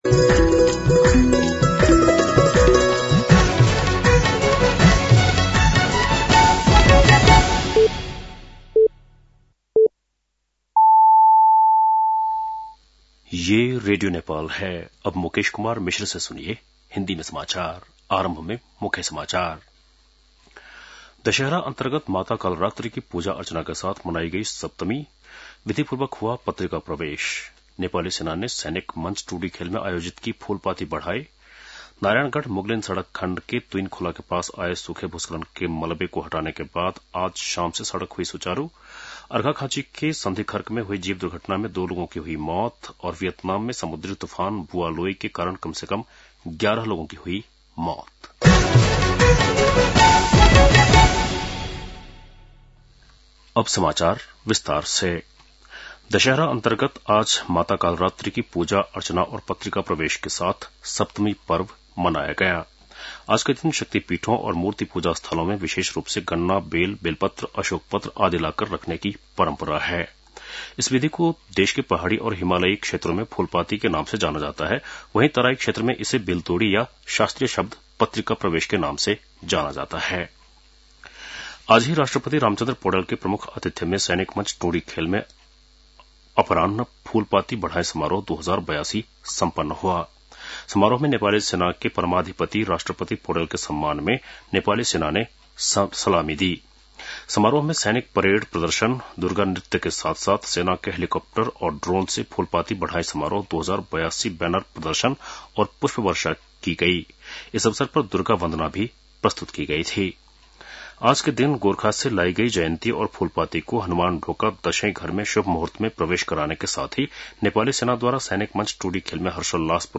बेलुकी १० बजेको हिन्दी समाचार : १३ असोज , २०८२